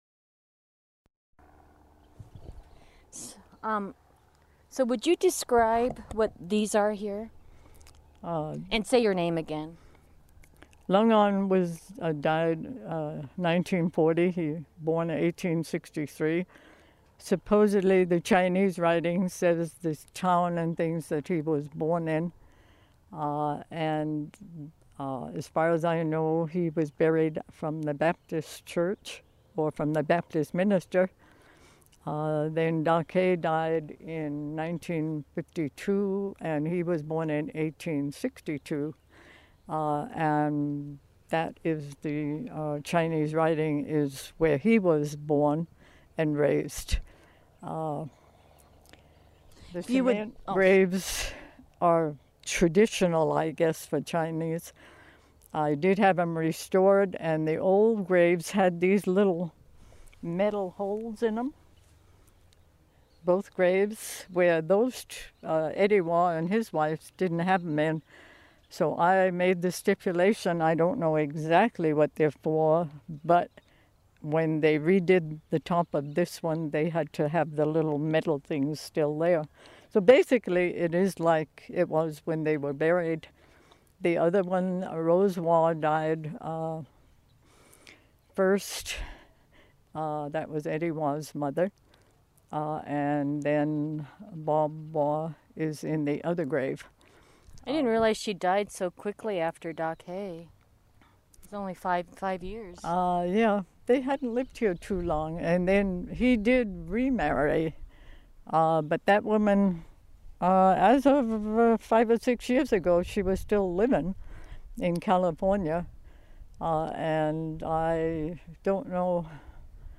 Kam Wah Chung Museum Tour